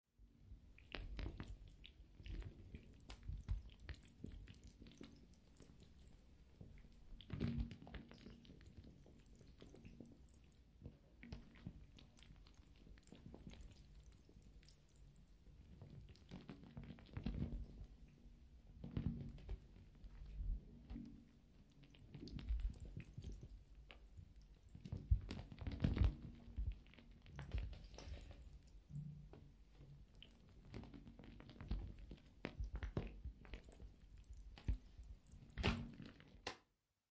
Звуки осьминога
Звук медленного ползания осьминога по стене щупальцами